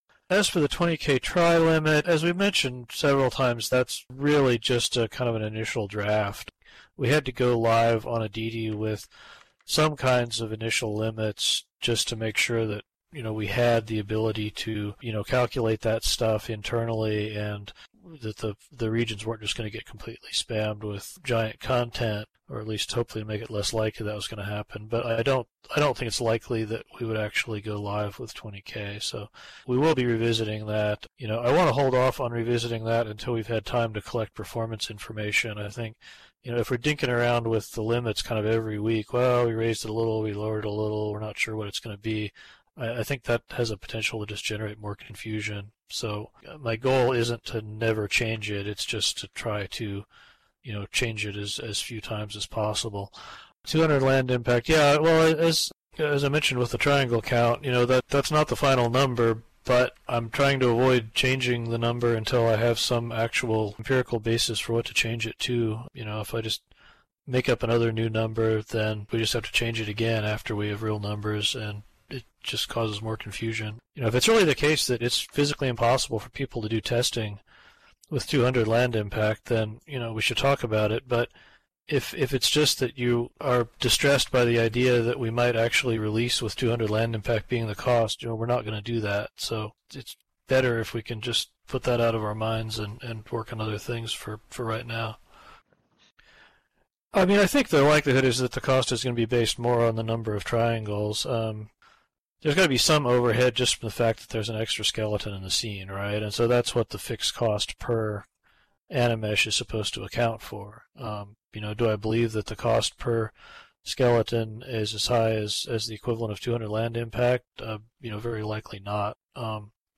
Note that some of the audio extracts have been gathered from various points in the meeting and presented here as a concatenated whole by subject heading for ease of reference.